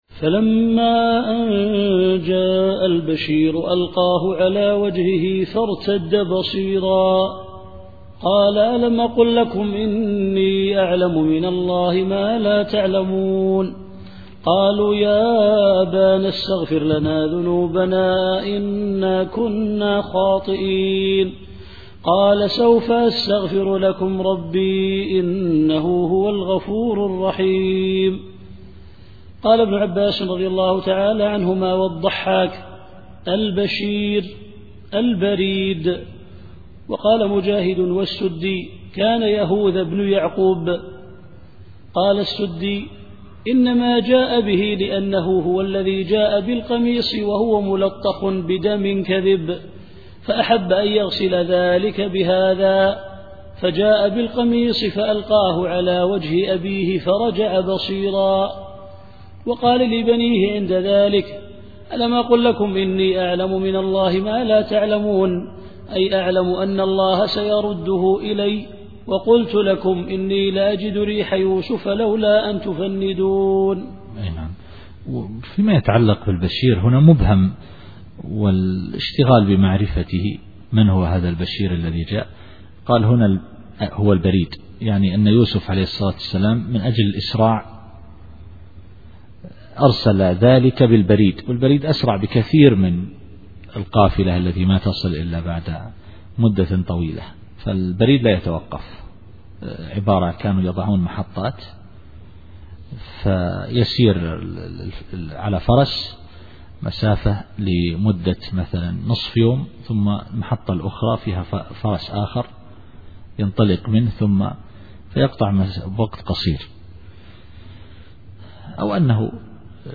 التفسير الصوتي [يوسف / 96]